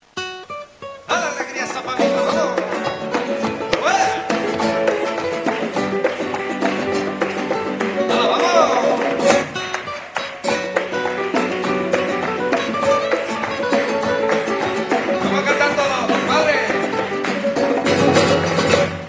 Live In Concert